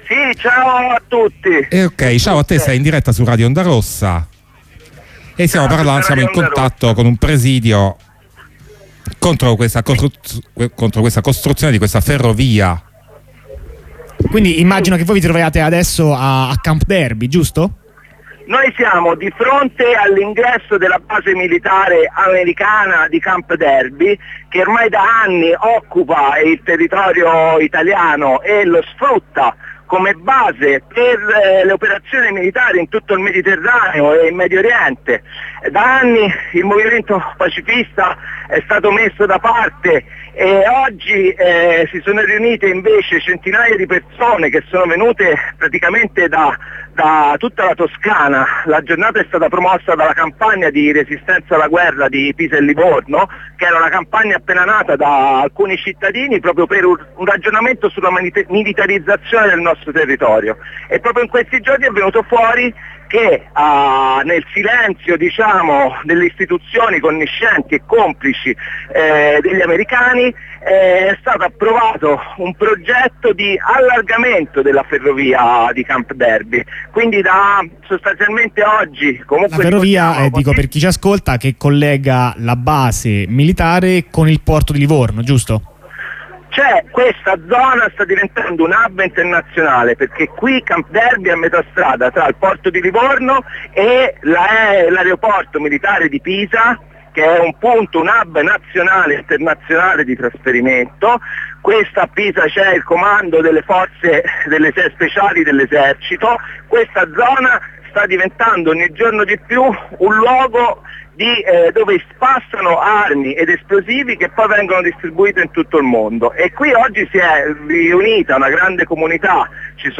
Da Camp Darby
Presidio fuori dalla base militare di Camp Darby per protestare contro i progetti di allargamento della ferrovia che collega la base al porto di Livorno. Progetti noti alle istituzioni ma tenuti nascosti alla popolazione, e che sottendono un'intenzione di aumentare la capacità militare della base.